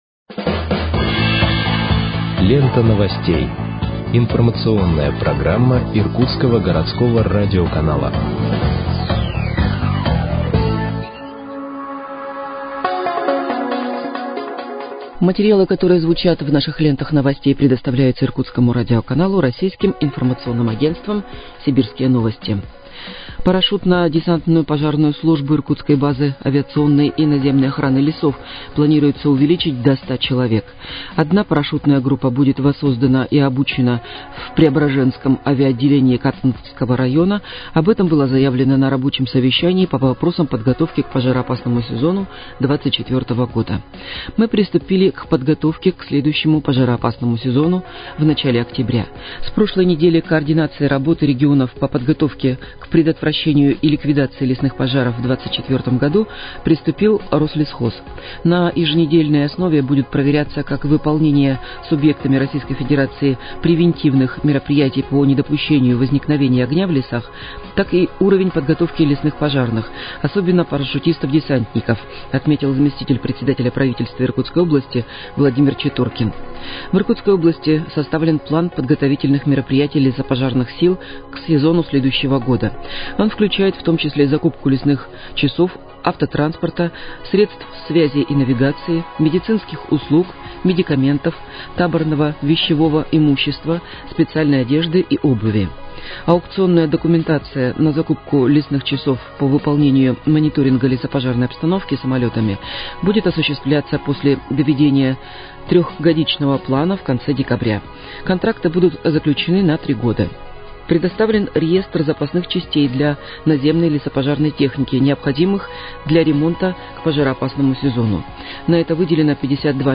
Выпуск новостей в подкастах газеты «Иркутск» от 04.12.2023 № 1